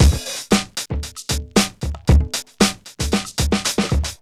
FUNKYCHIC115.wav